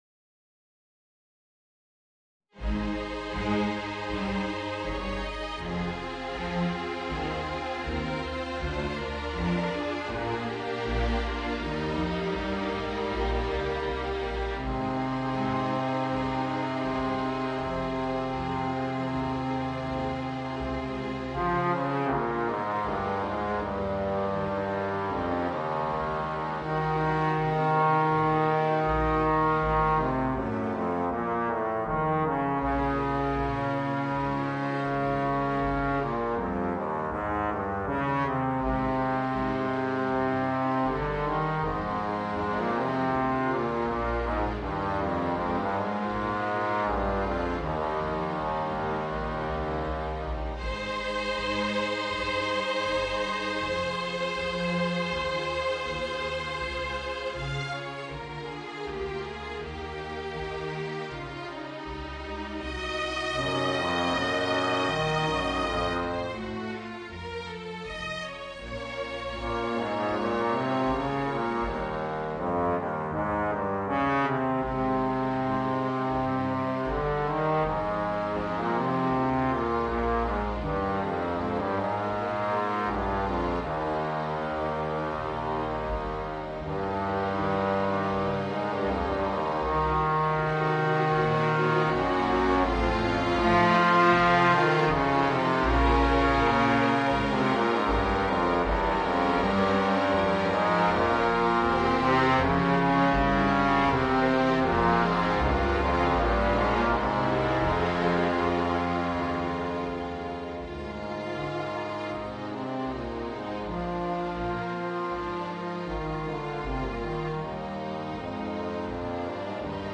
Voicing: Bass Trombone and String Orchestra